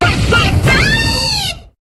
Cri de Goupelin dans Pokémon HOME.